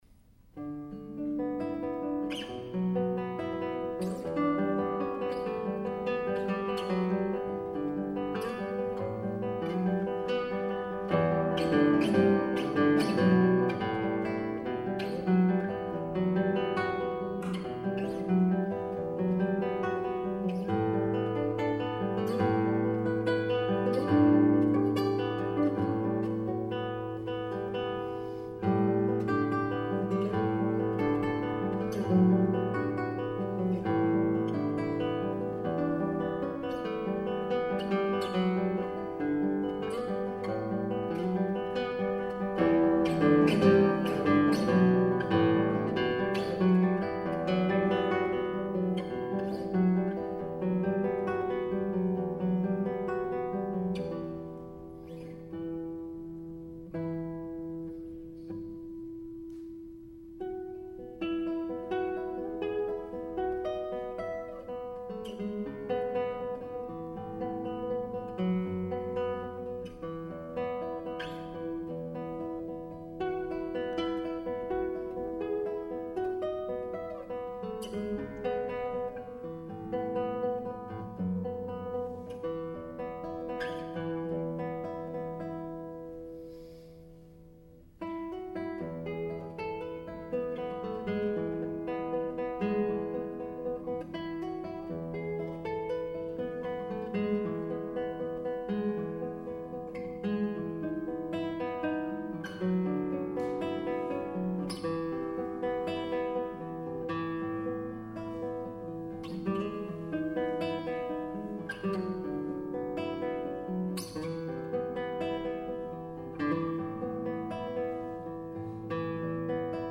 Triptych for solo guitar
premiere performance